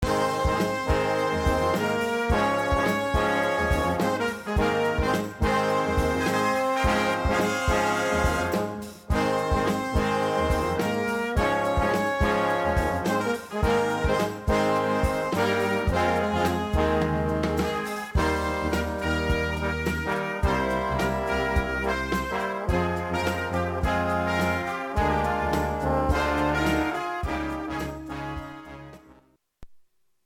Innehåll: 81,8 % brass, 18,2 % slagverk.